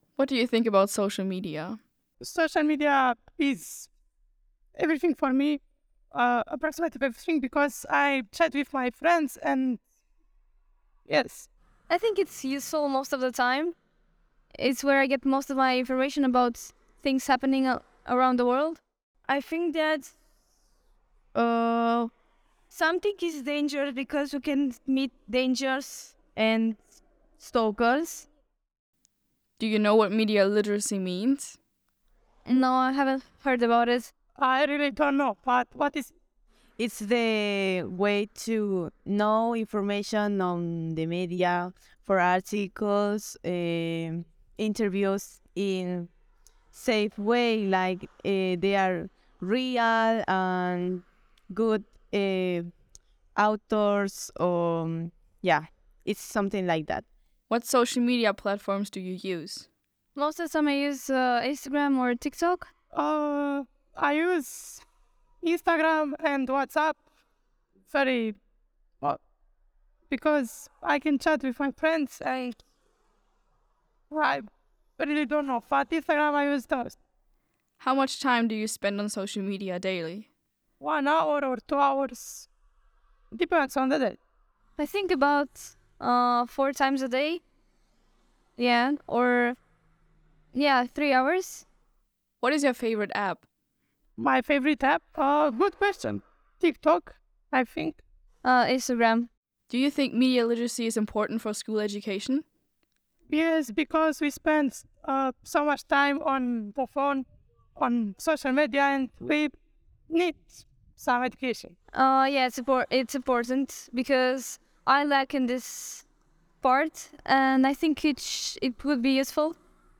CiMe_Umfrage_Gruppe1_fertig.wav